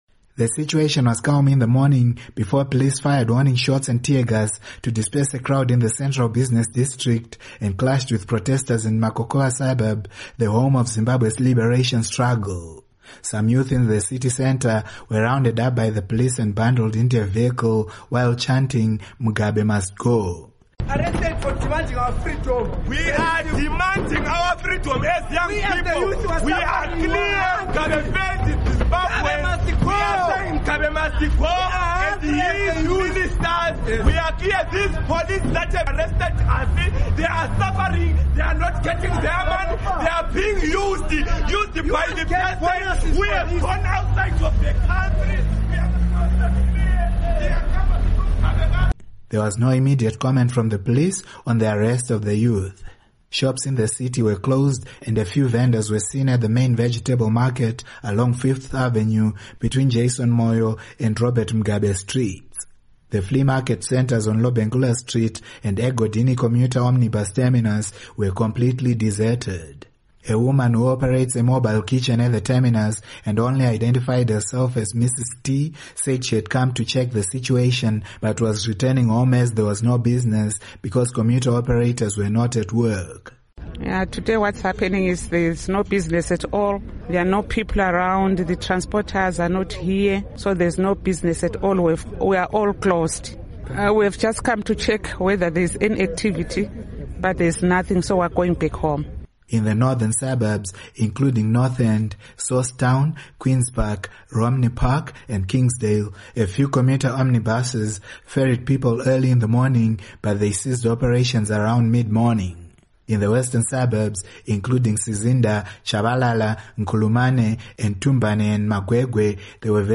Report On Bulawayo